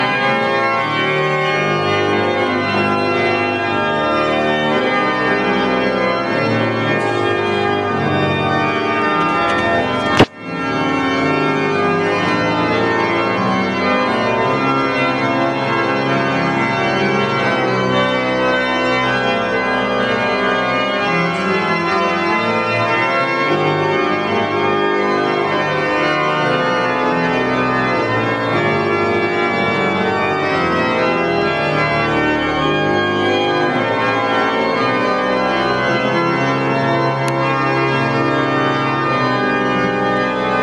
Orgelweihe in Bad Frankenhausen
Dann kam der große Moment, nach der Weihe erklangen die ersten Töne der restaurierten Orgel.
So klingt die Strobel-Orgel
Dieser Ausschnitt kann den Klangumfang des wunderbaren Instrument nur unvollkommen wiedergeben.